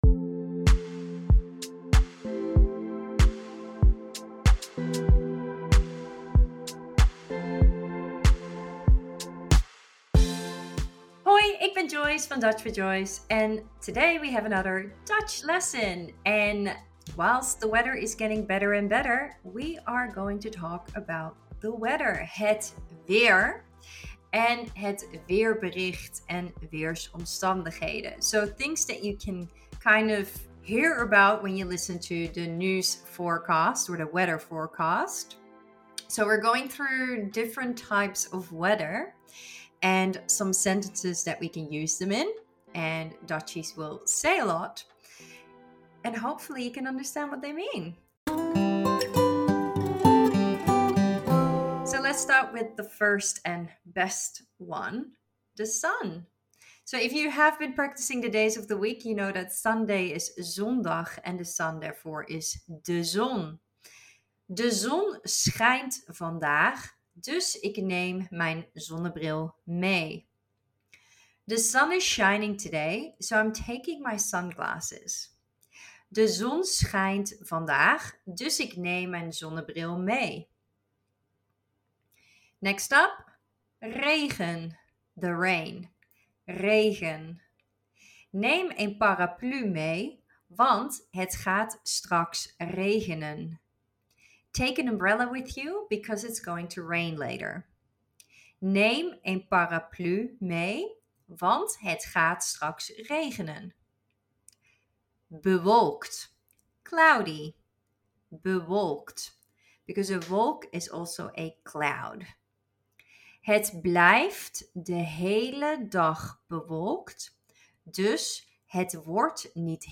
The weather is always a popular topic of conversation in the Netherlands. In this mini-lesson